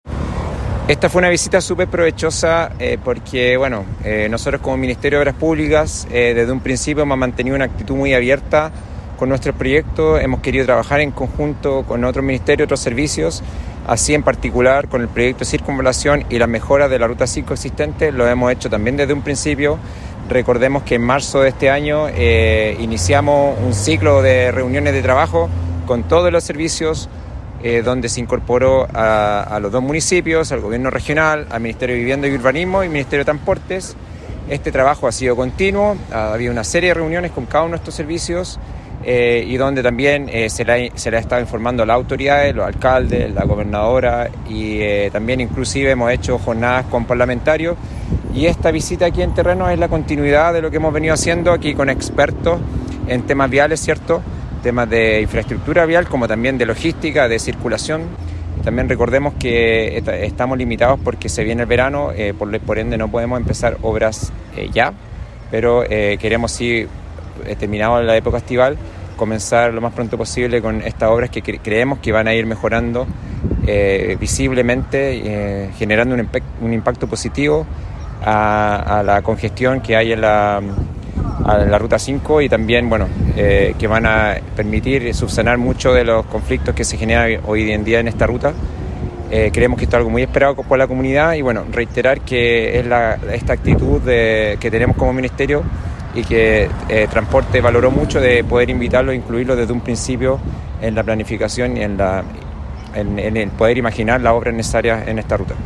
En este contexto, Sandoval calificó la jornada como “provechosa”, indicando que esta visita en particular es la continuidad de lo que como Ministerio han estado trabajando, con expertos en infraestructura vial y de logística de circulación.